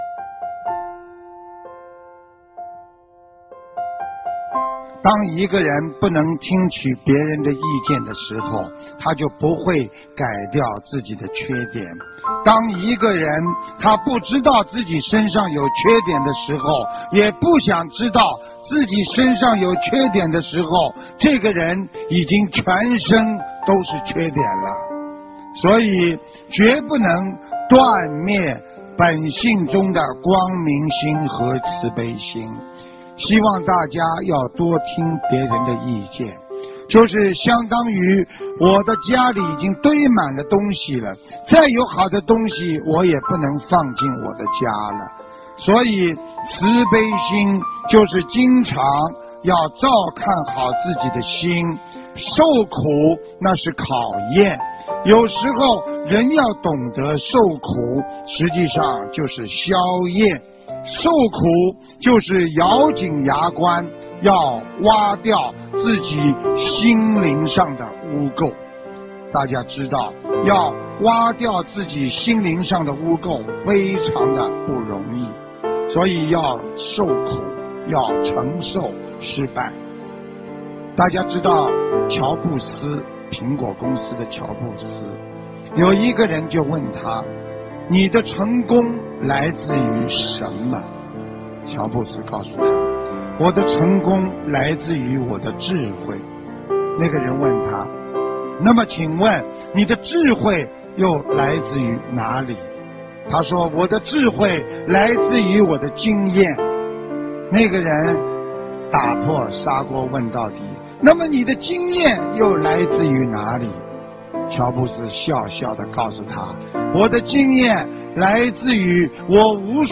视频：115.受苦实际上就是消业！新西兰2014年4月27日 - 法会节选 心灵净土